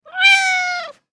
دانلود صدای گربه کوچولو برای زنگ موبایل از ساعد نیوز با لینک مستقیم و کیفیت بالا
جلوه های صوتی